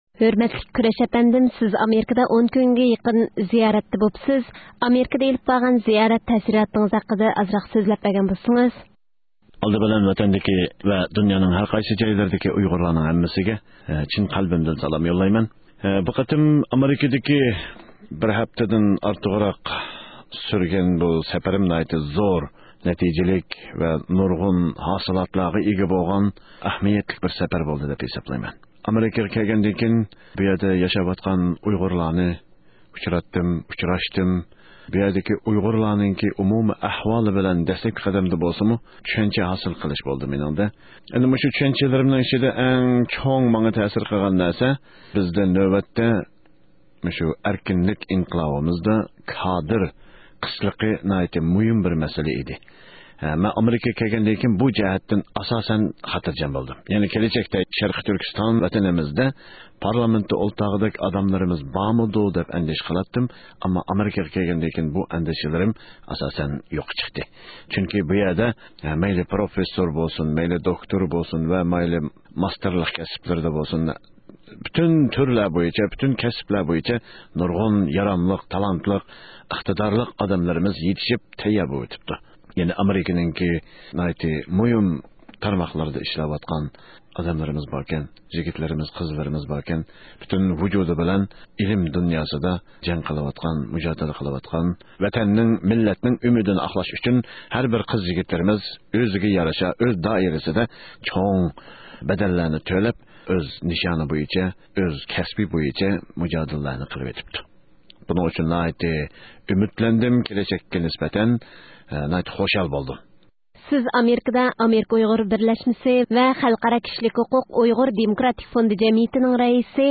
ئۇ ئامېرىكىدىن ئايرىلىش ئالدىدا ئىستانسىمىزدا زىيارەتتە بولۇپ، ئۆزىنىڭ ئامېرىكا تەسىراتلىرى ھەققىدە توختالدى.